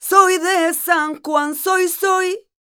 46a06voc-f#m.wav